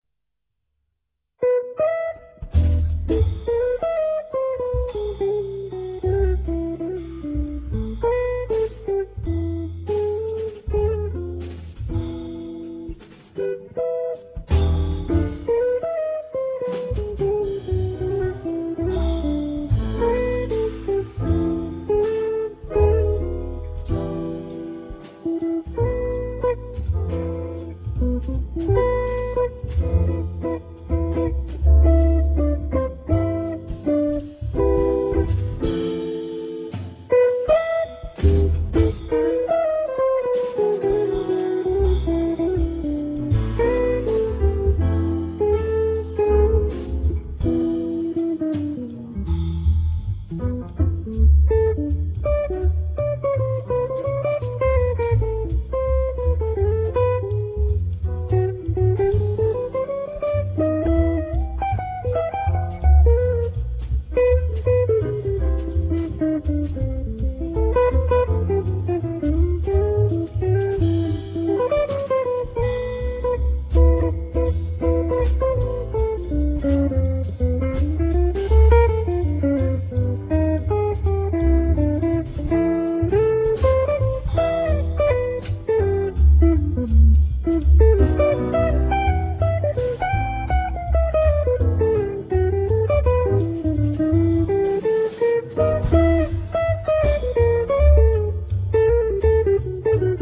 piano digitale
Theme + guitar solo
Radio quality 1'40'' 254 K